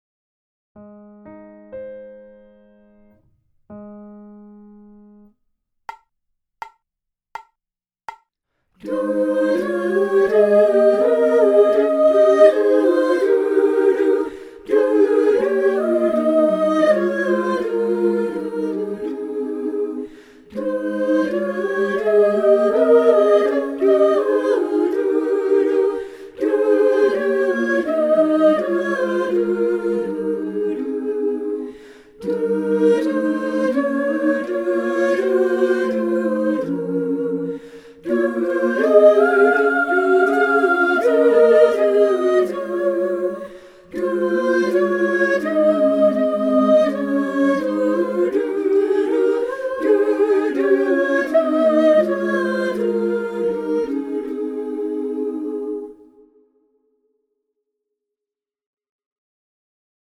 Bass
Bass.mp3